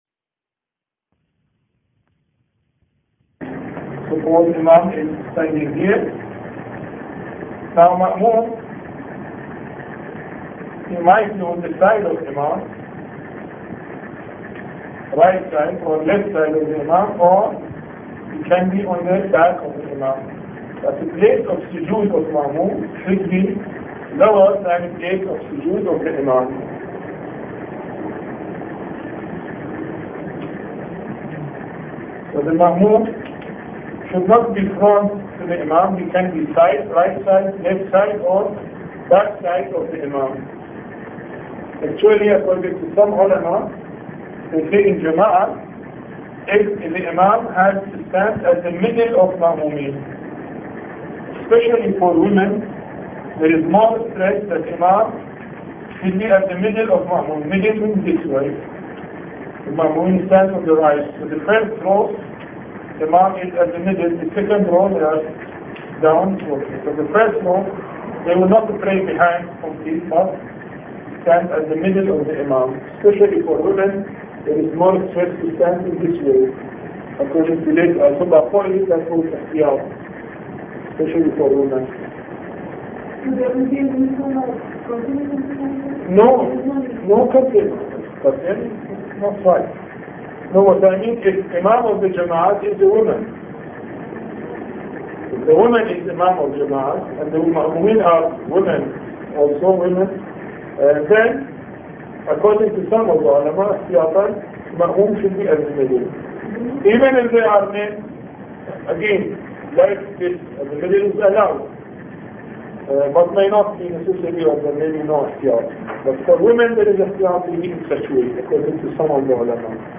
A Course on Fiqh Lecture 16